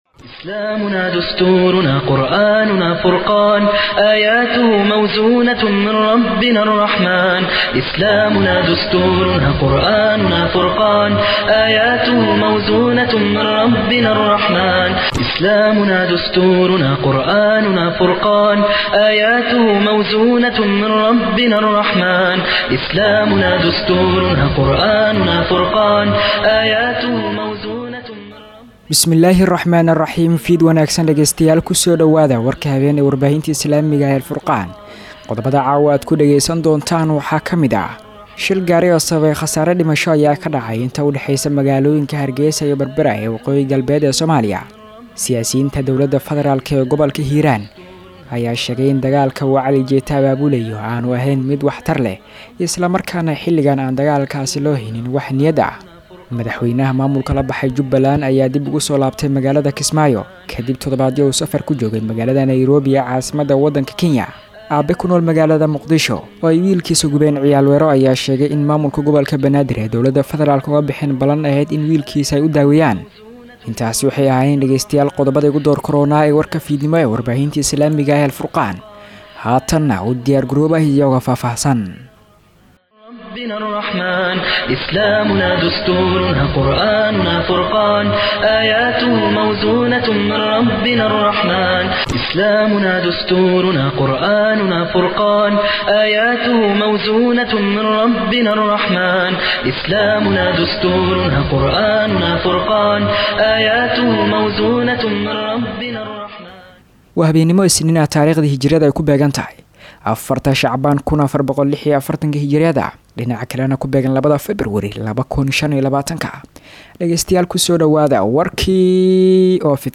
Dhageystayaasha iyo akhristayaasha ku xiran idaacadda Al-Furqaan waxay had iyo goor halkan kala socon karaan Wararka iyo Barnaamijyada ay Idaacaddu baahiso.